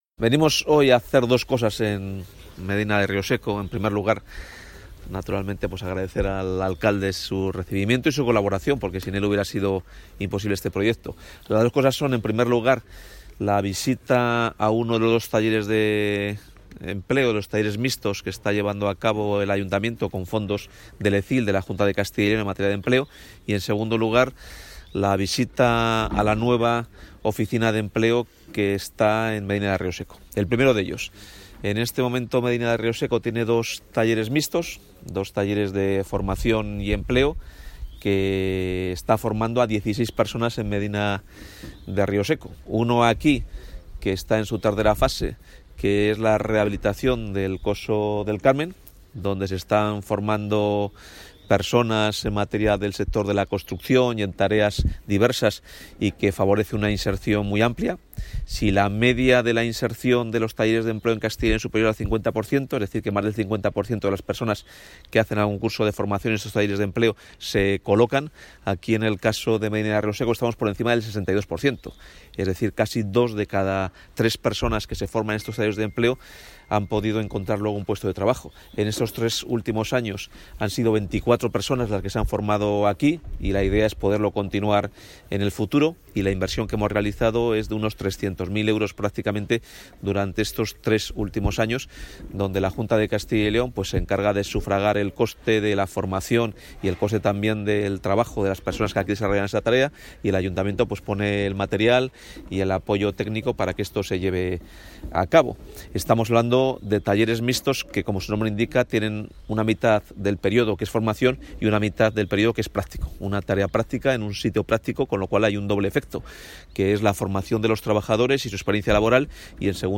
Declaraciones del consejero de Empleo.